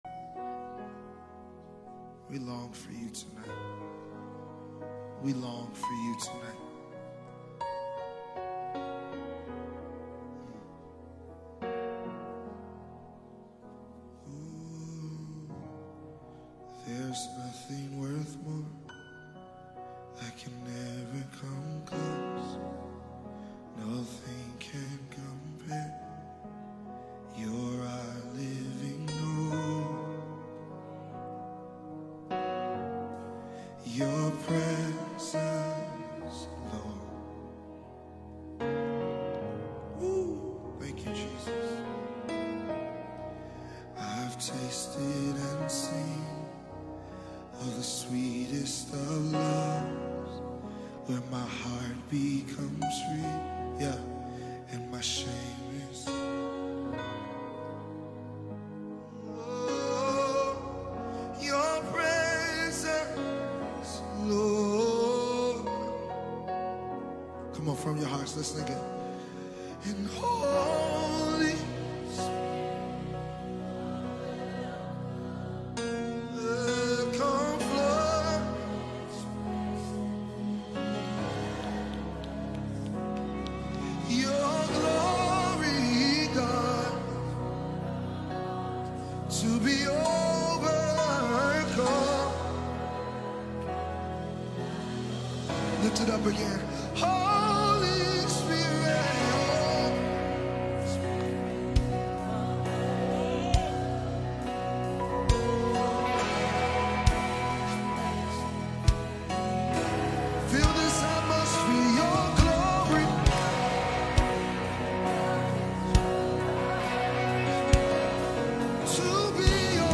live ministration